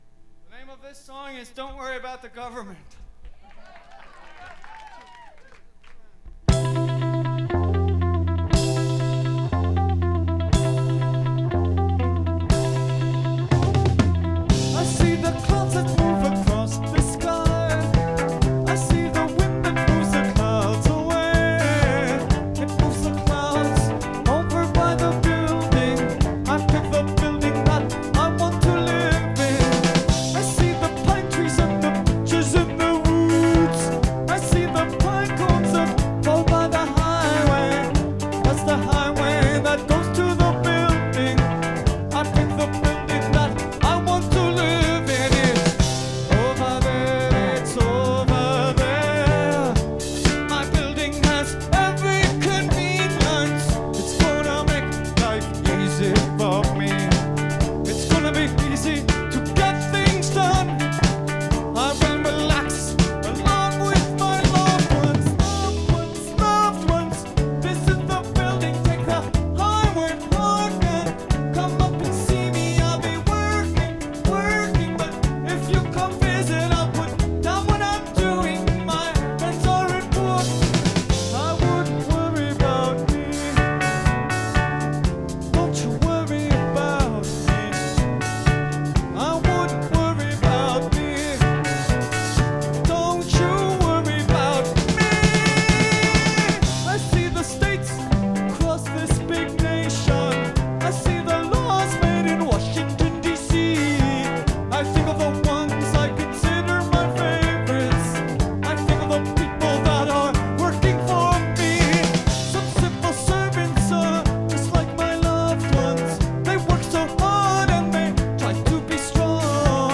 Recorded November 17th, 1977 in Massachusetts
Source: vinyl rip